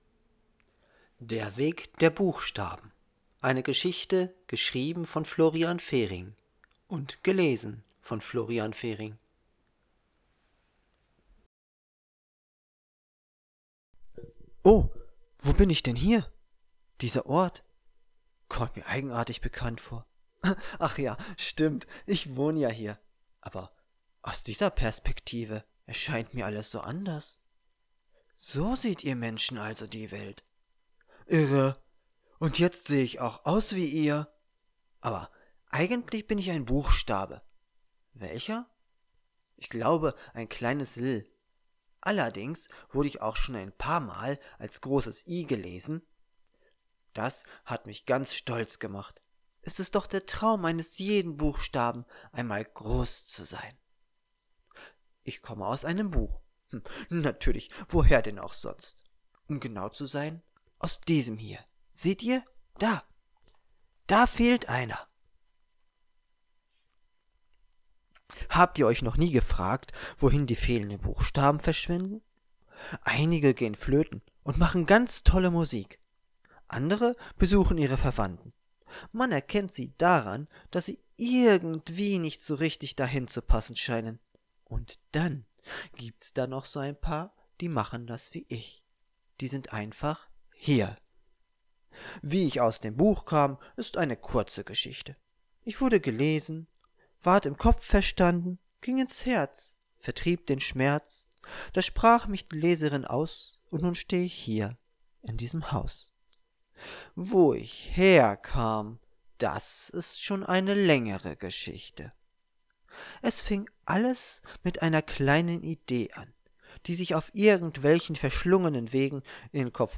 Ebook (pdf) Ebook (epub) AudioBook (mp3) Der Weg der Buchstaben Geschrieben von Florian Fehring Oh.